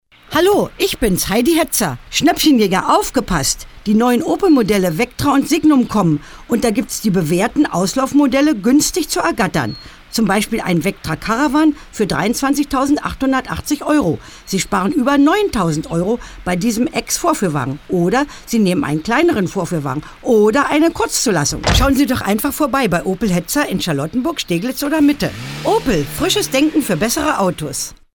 Radio: ALLE MAL HERHÖREN!